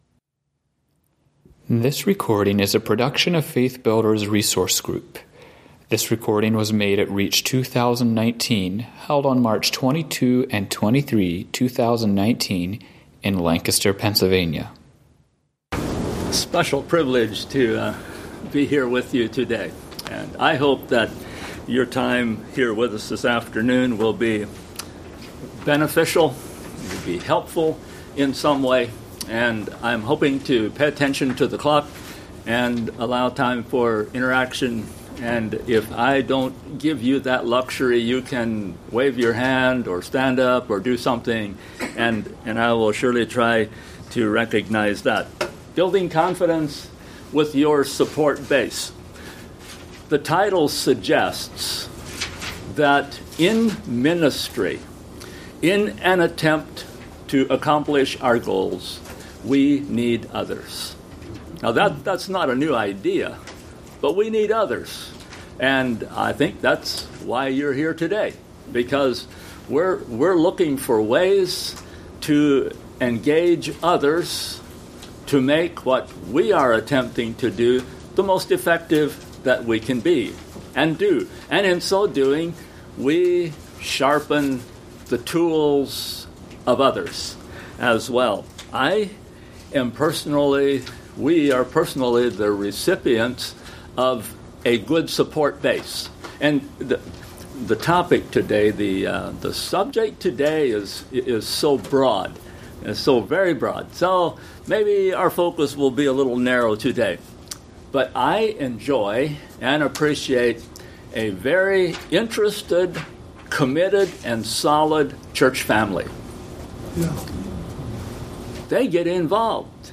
Home » Lectures » Building Confidence with Your Support Base